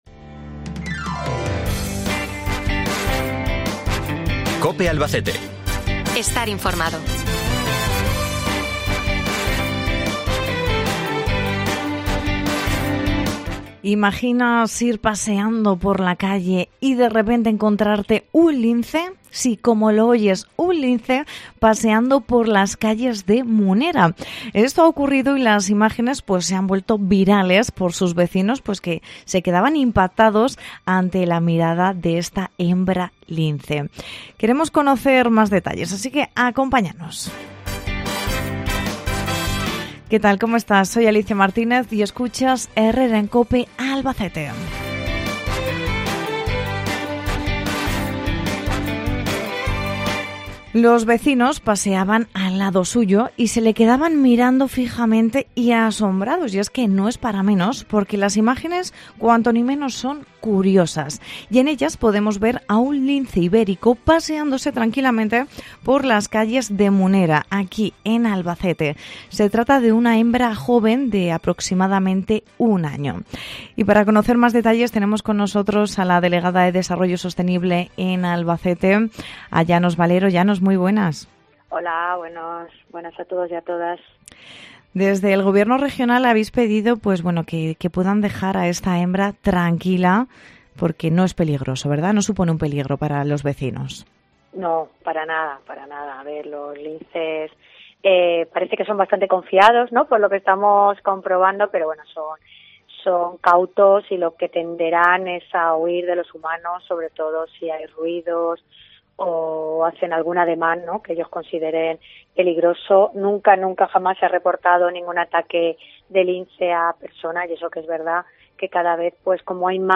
Entrevista con Llanos Valero, delegada provincial de Desarrollo Sostenible